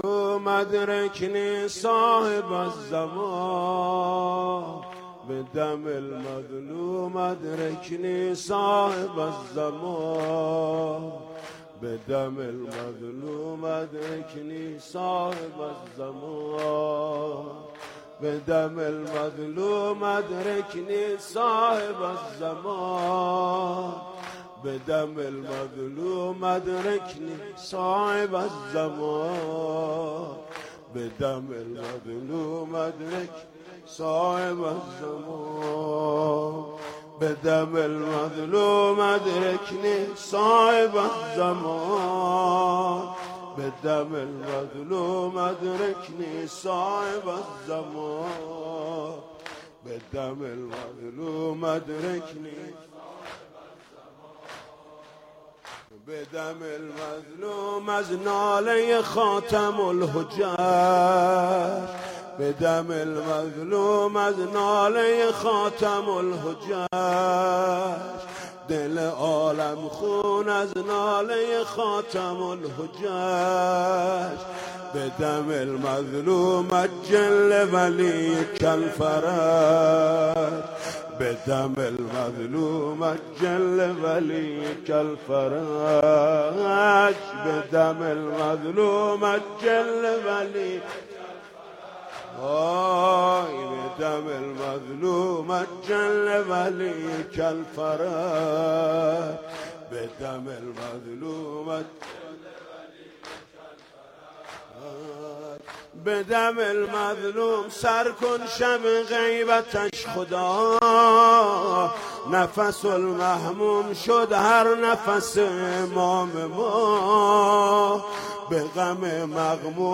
نوحه جديد
مداحی محرم
مداحی صوتی